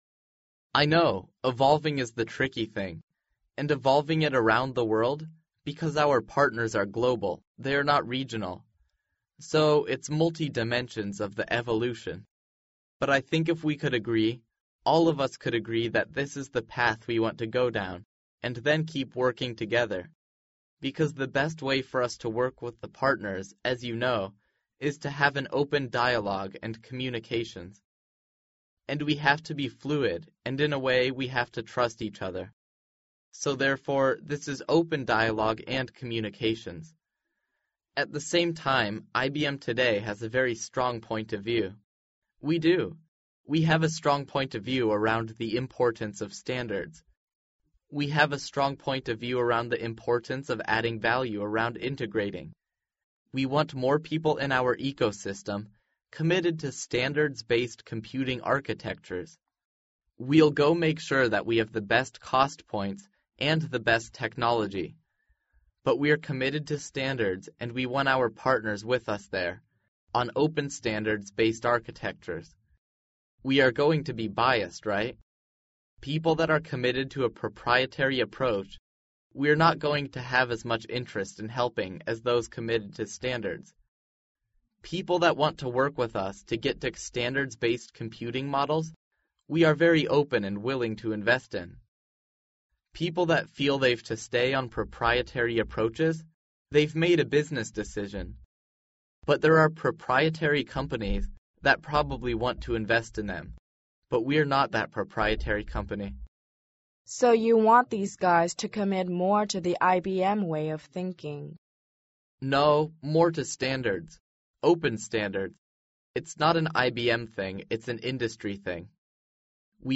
世界500强CEO访谈 第27期:IBM山姆彭明盛 营销战略和整合有关(3) 听力文件下载—在线英语听力室